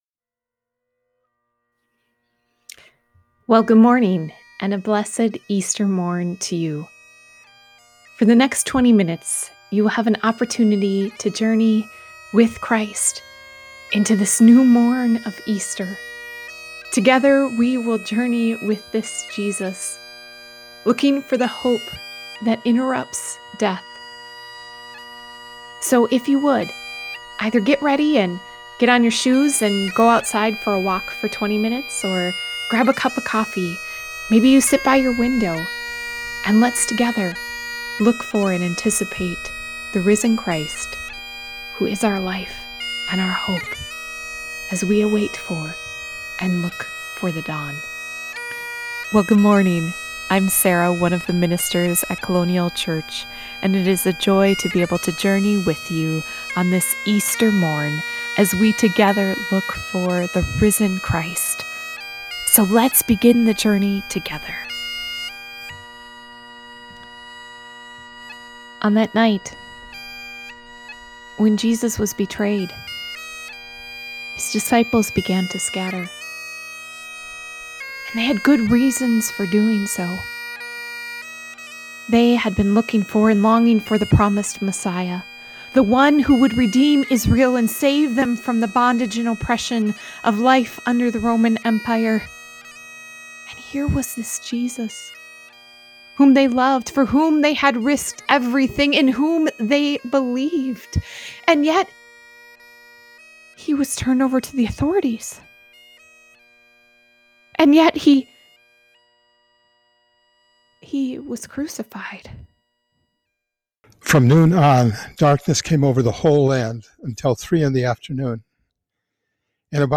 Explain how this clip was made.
Obviously, in this time of Coronavirus, we won’t be gathering in person so I created a podcast experience of the sunrise service I was supposed to lead.